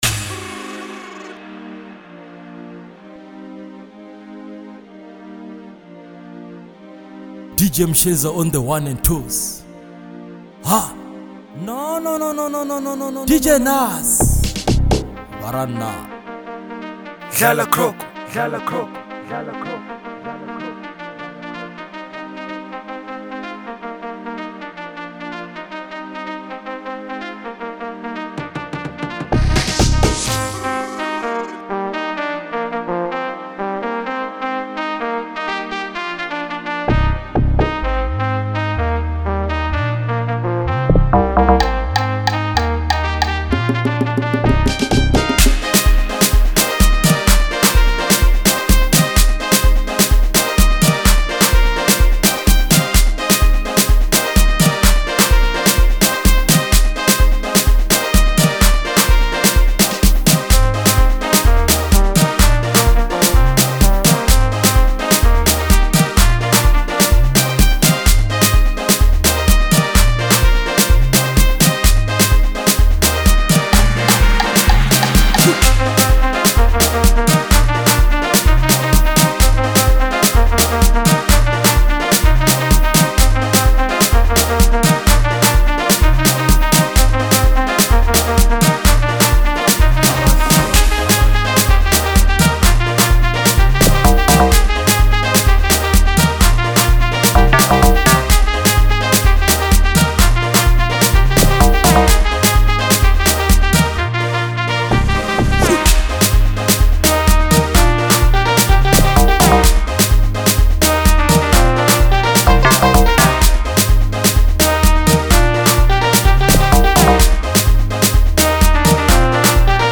Genre: Lekompo / Bolo House